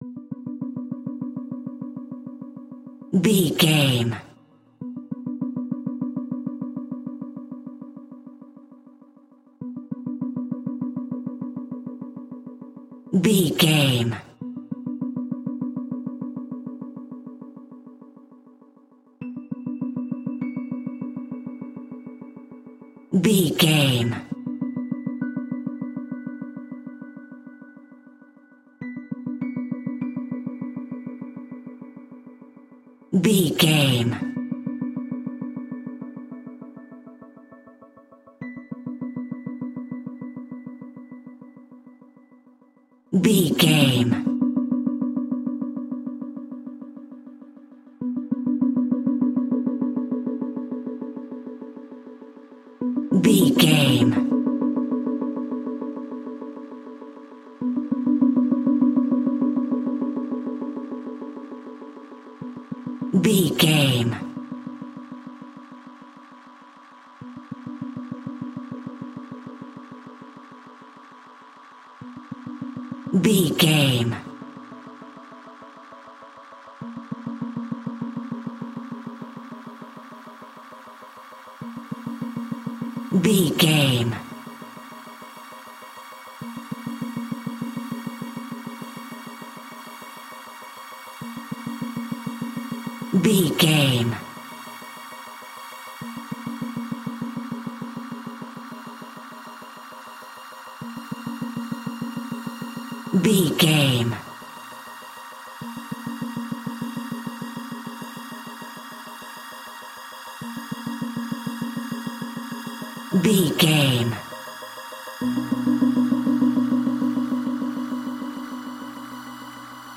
Scary Horror 1980s Synths.
Aeolian/Minor
ominous
eerie
synthesiser
piano
horror music
Horror Pads